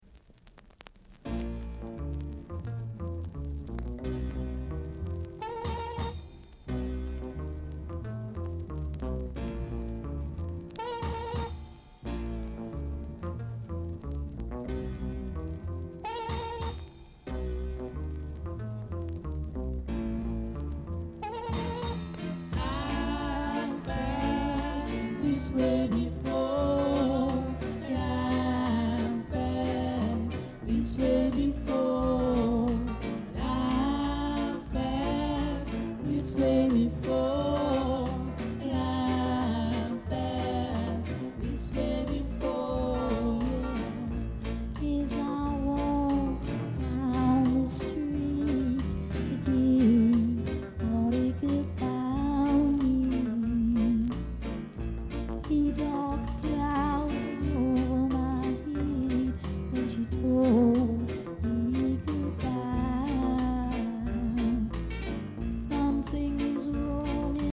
R.Steady Vocal Group
Nice rock steady vocal w-sider!